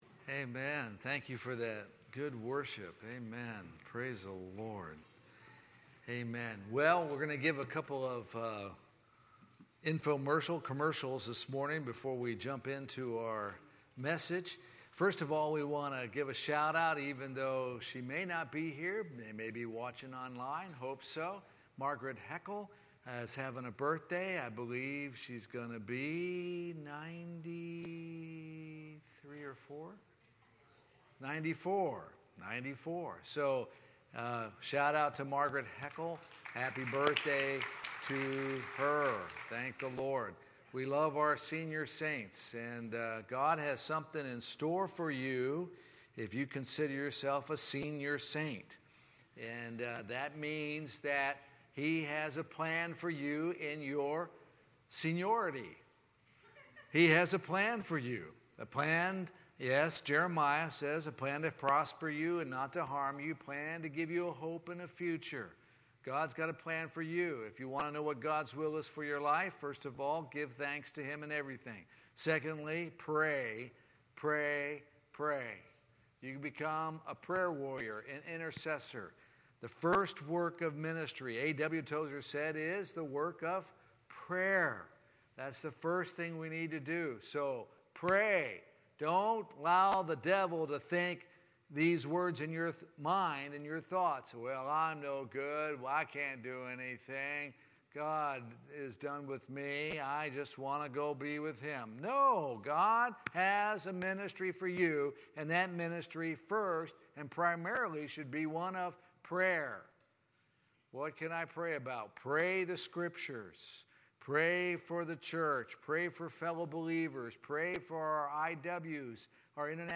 Sermons | Alliance church of Zephyrhills